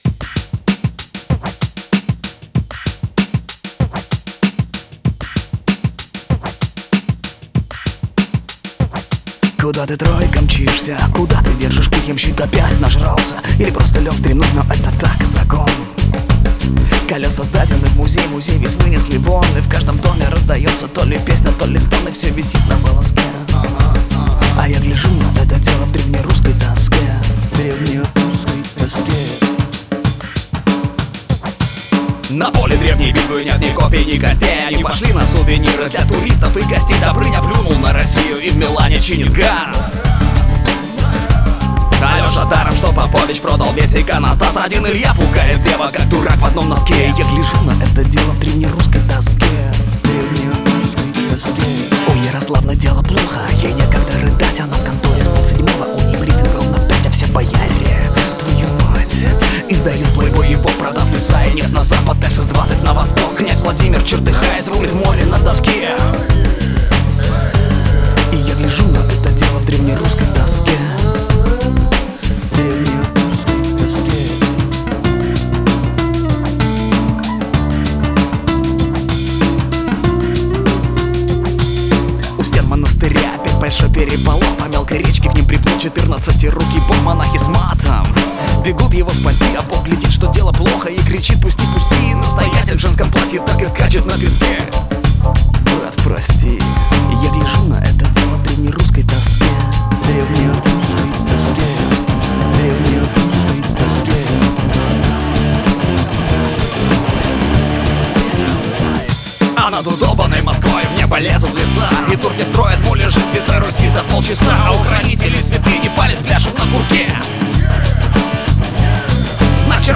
Здесь просто ритм.